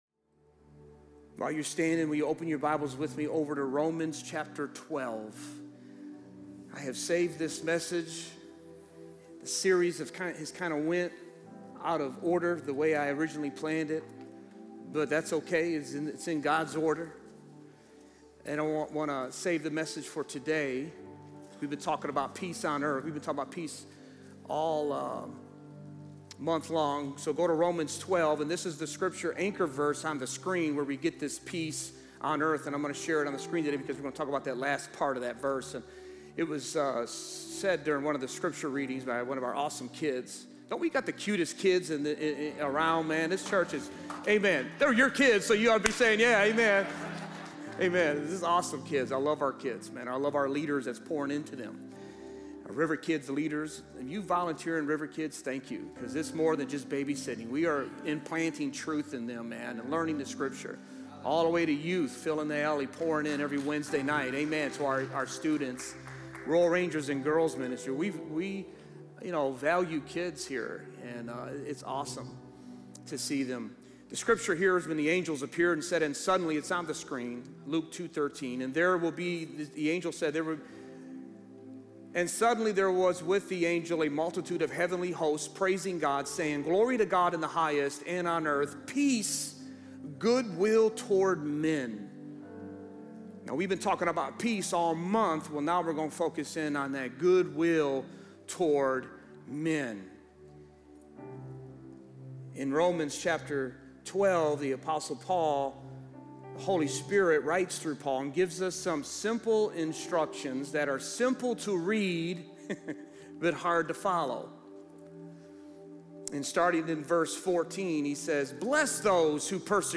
Sermons | River of Life Church